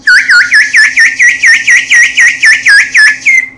Siren
描述：Siren sound
标签： siren
声道立体声